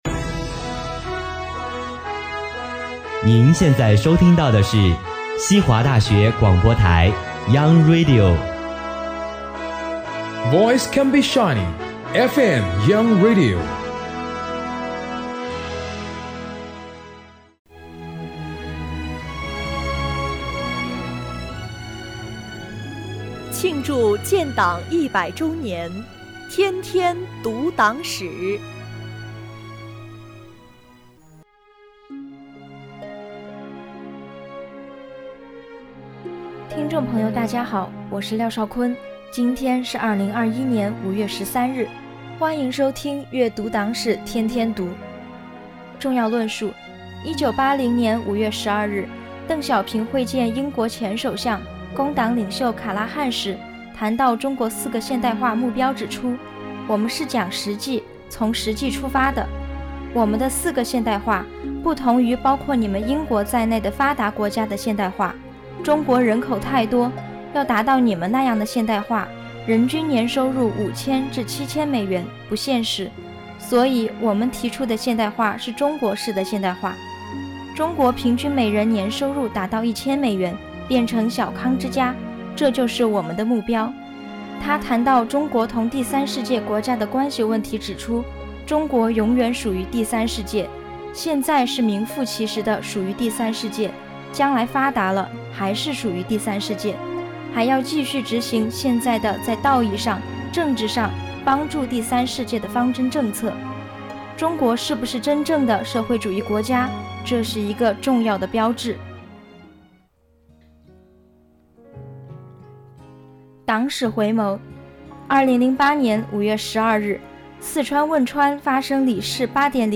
西华学子悦读党史